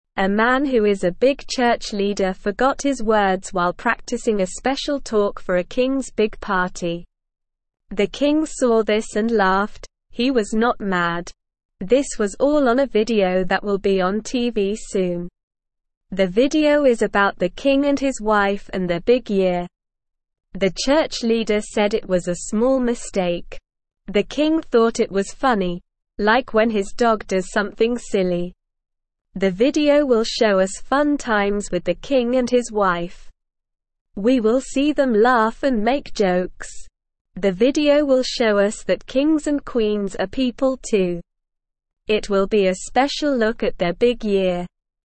Slow
English-Newsroom-Beginner-SLOW-Reading-The-Kings-Funny-Video-A-Special-Look.mp3